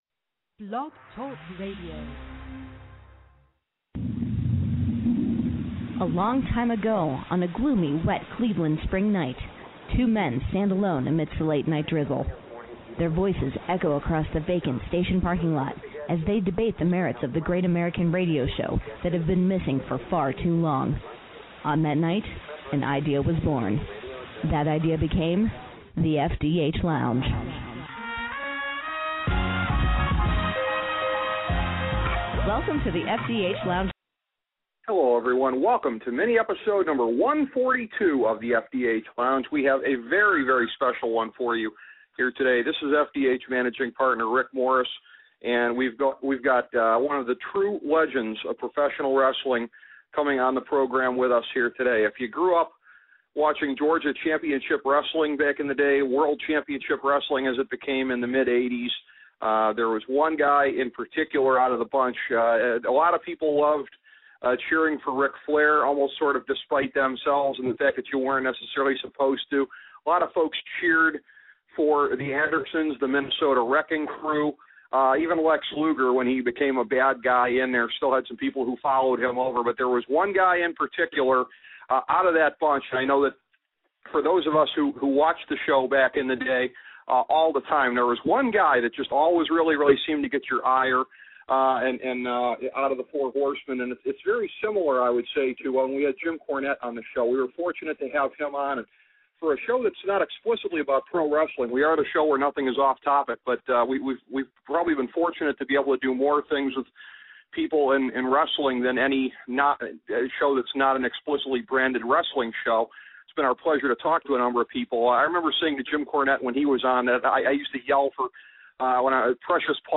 A conversation with Tully Blanchard